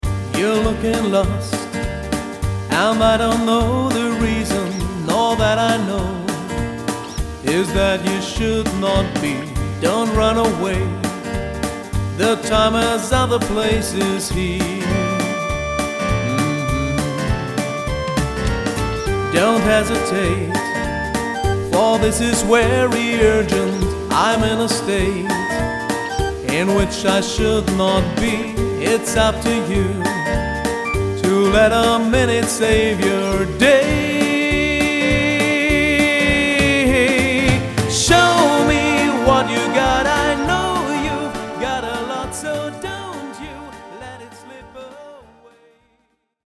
Sängerin, Sänger/Keyboard, Sänger/Schlagzeug